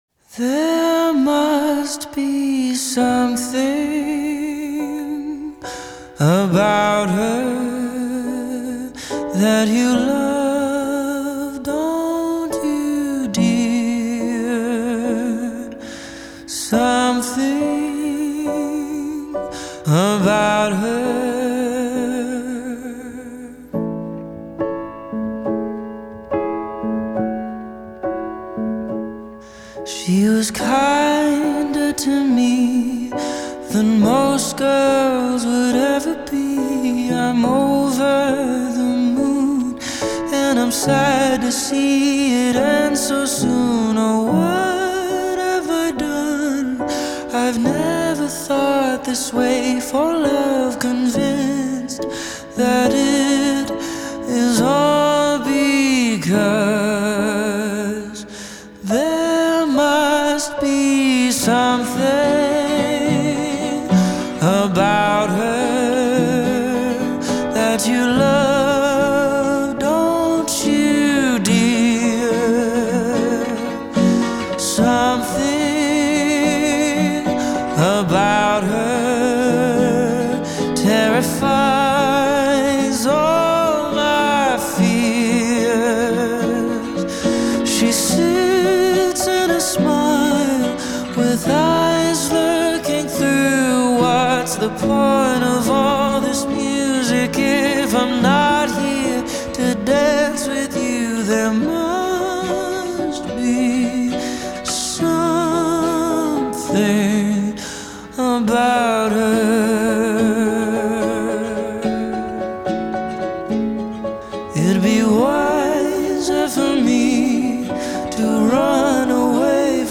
Pop Rock, Indie Pop, Ballad